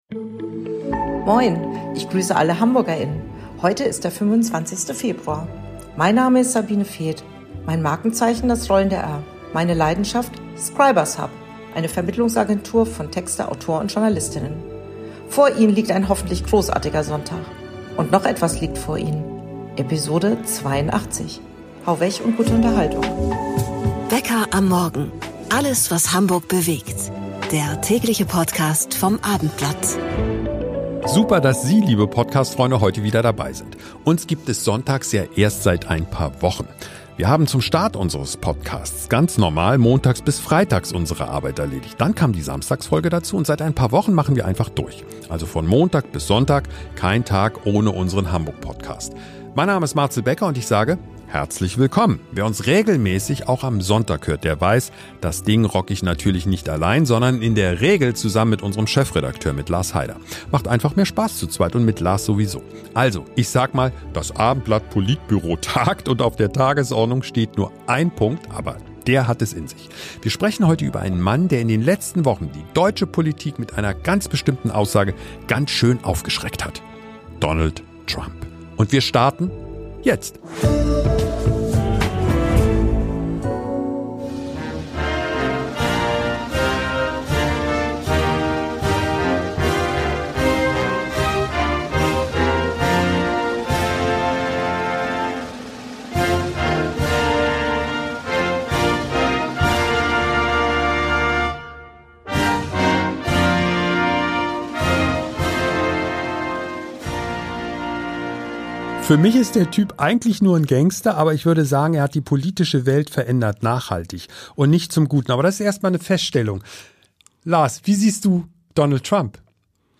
Wie Donald Trump die politische Diskussion diktiert Warum Olaf Scholz vielleicht auf Trumps Wiederwahl hofft Die „besten“ Ausschnitte aus Trump-Reden